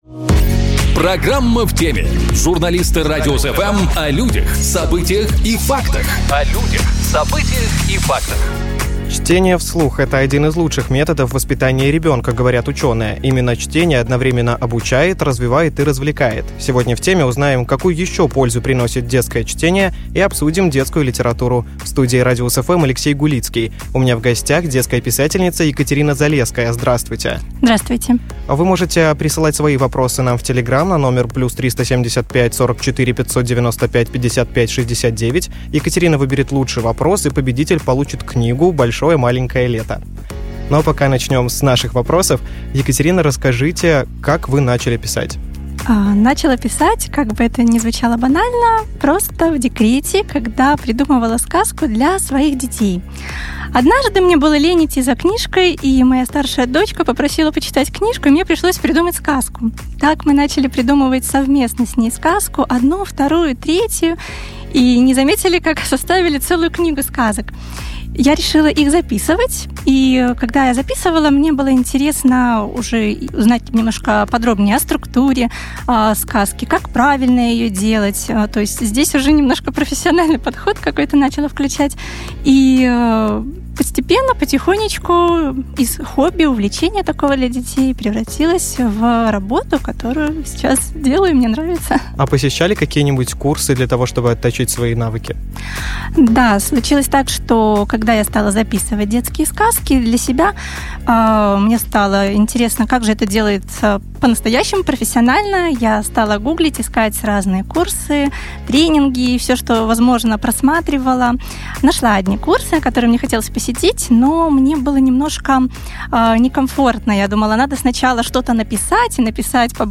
Сегодня "В теме" узнаем, какую ещё пользу приносит детское чтение и обсудим детскую литературу. Об этом поговорим с детской писательницей